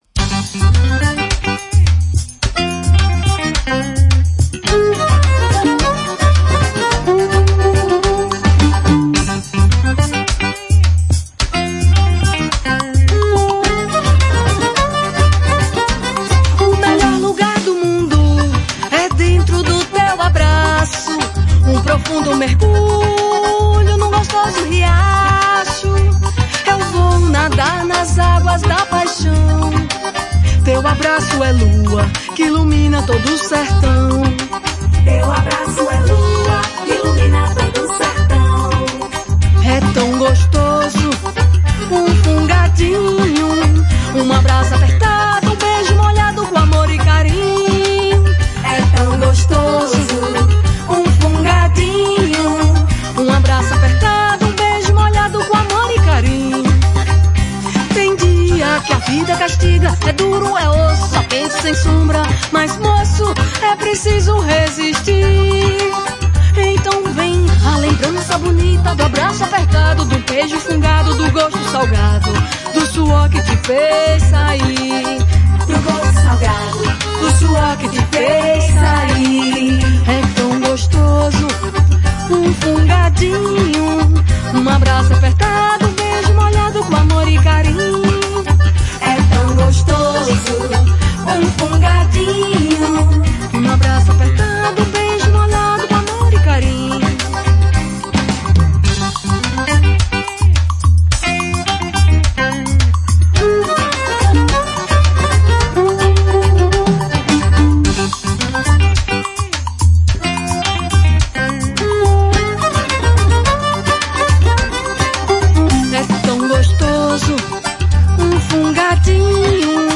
Acordeon
Violino
Percussão
Bateria
Guitarra
Contrabaixo